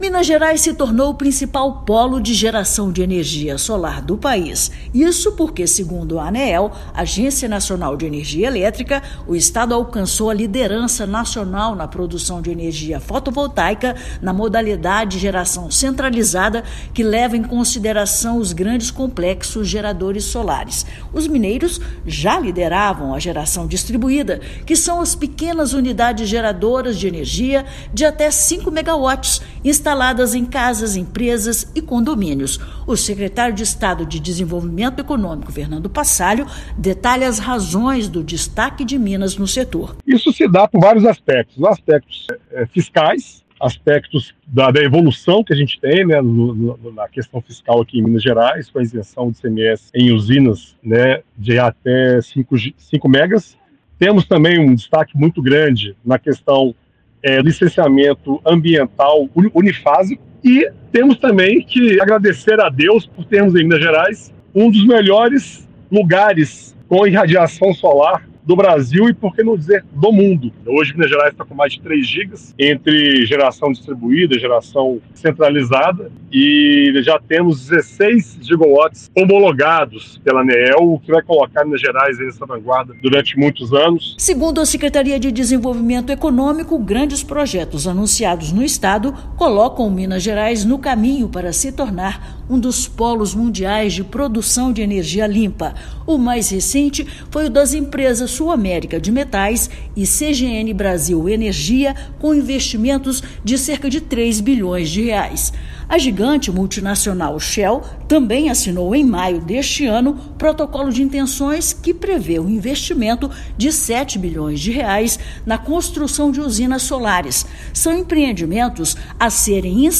[RÁDIO] Estado assume liderança nacional na produção de energia solar